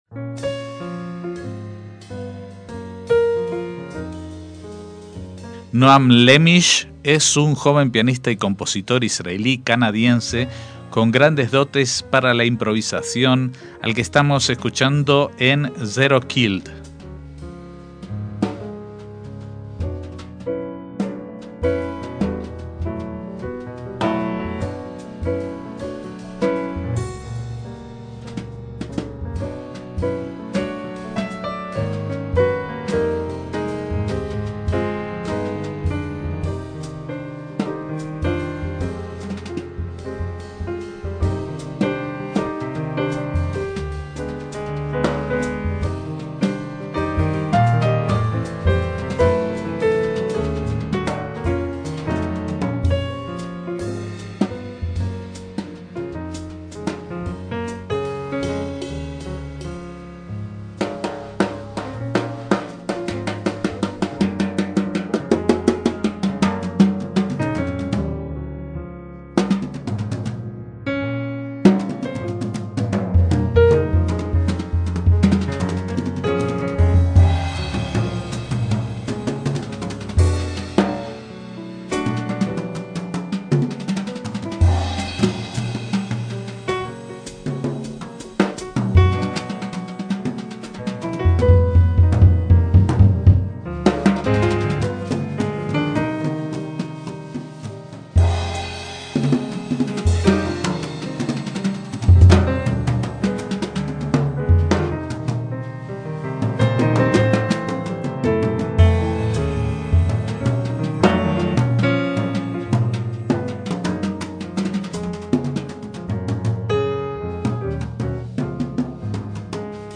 El pianista